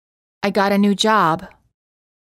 • ストレスをかける単語の主要な母音を長く発音する
※当メディアは、別途記載のない限りアメリカ英語の発音を基本としています